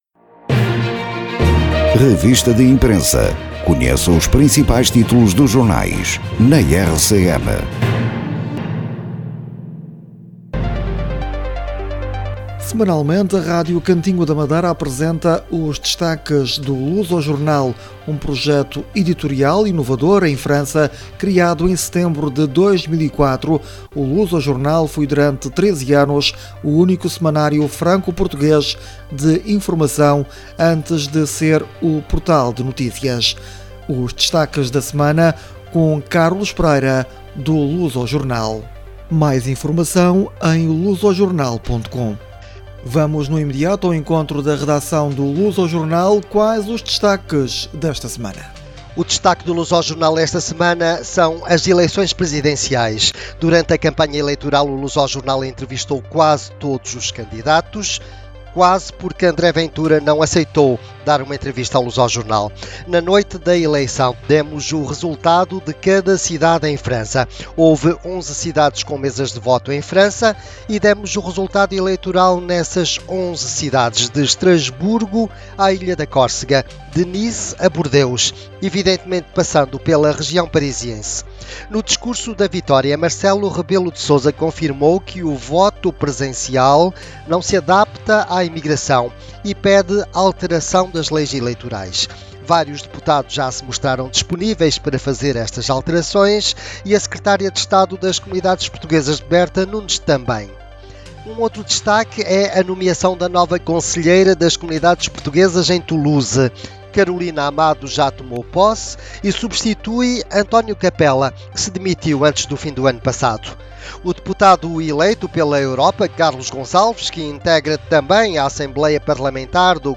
apresenta alguns dos temas em destaque.